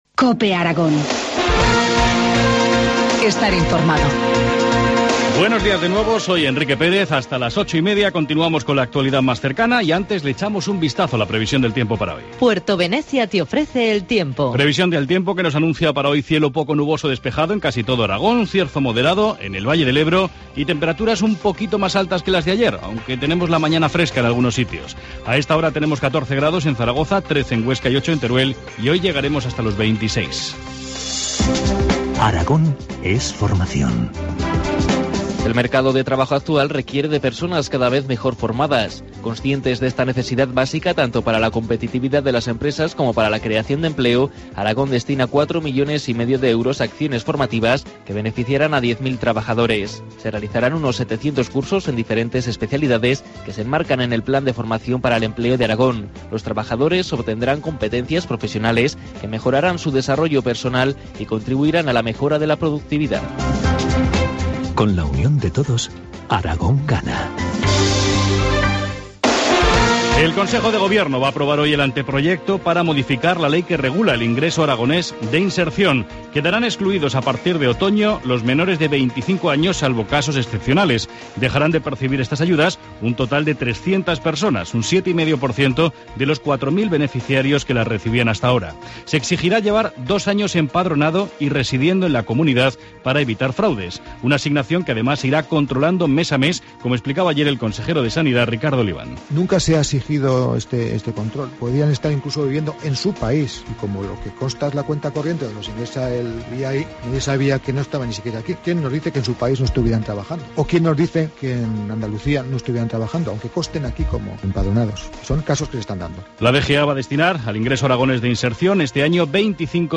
Informativo matinal, martes 25 de junio, 8.23 horas